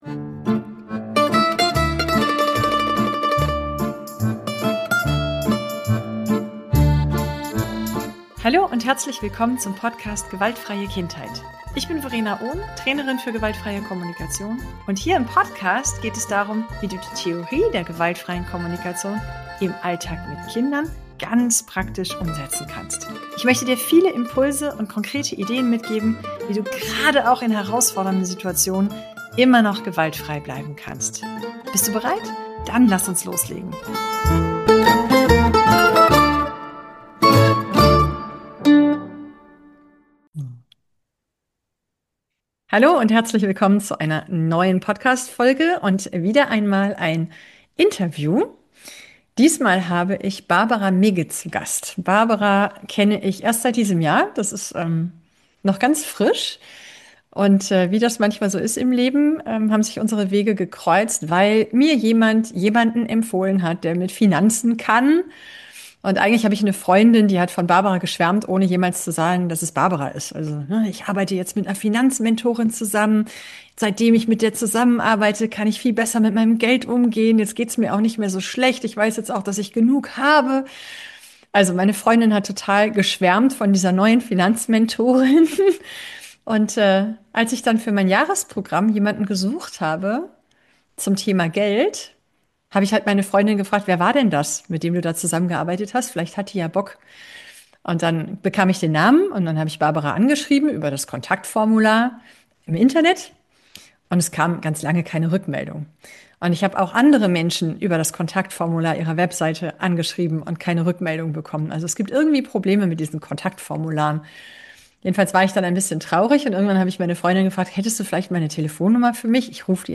Über Geld spricht man nicht - Interview